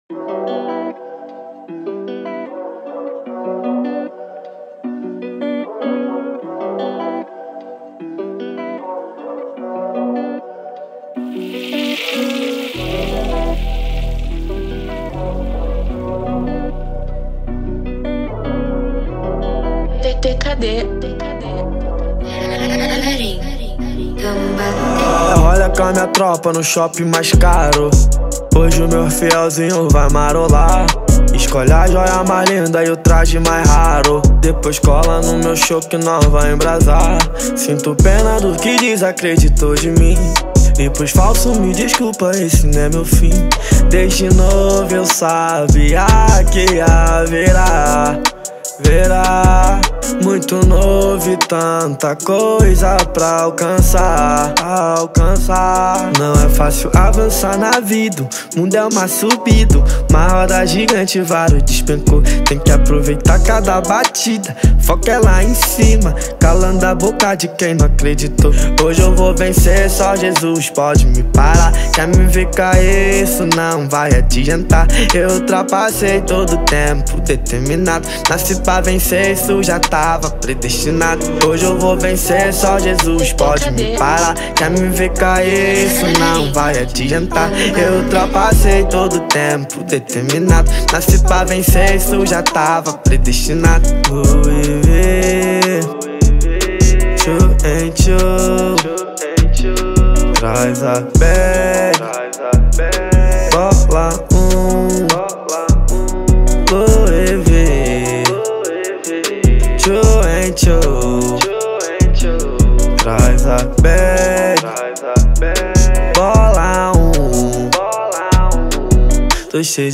2024-11-04 17:08:44 Gênero: Trap Views